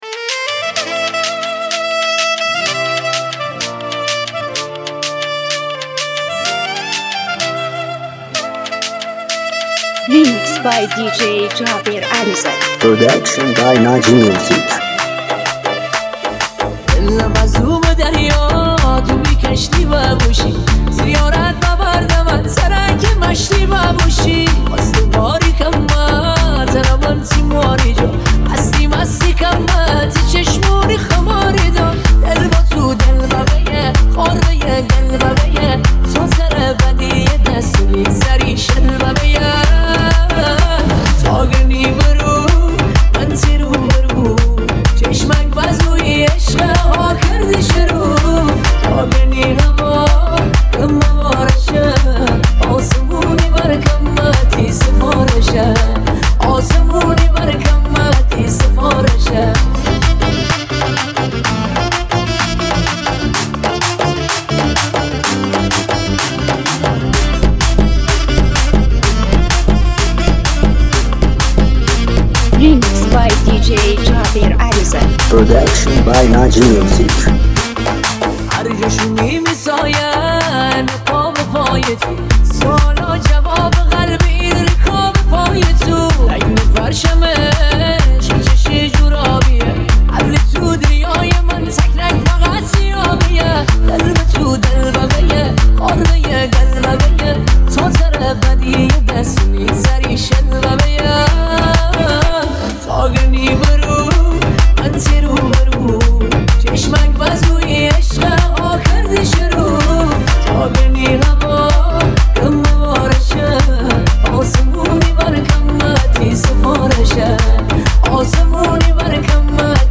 ورژن مازندرانی
(ریمیکس)